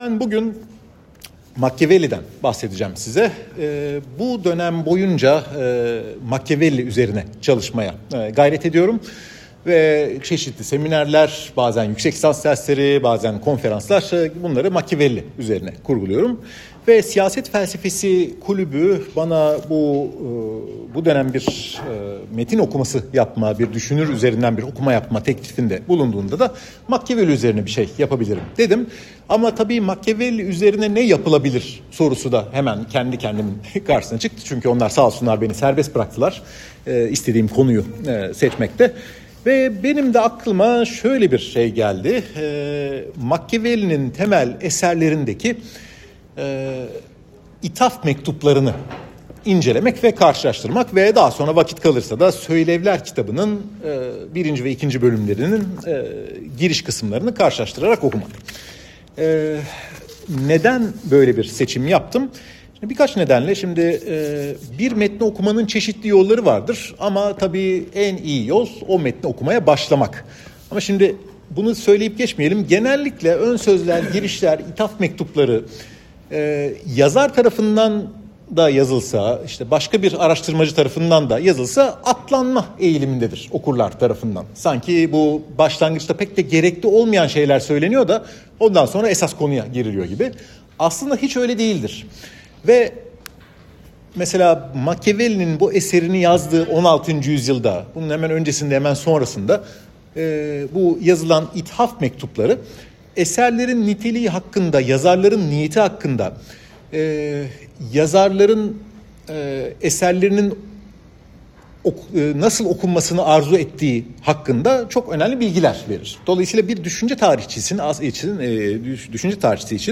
Mekan: İstanbul Üniversitesi Siyasal Bilgiler Fakültesi
Istanbul-Universitesi-IU-Beyazit-Kampusu.m4a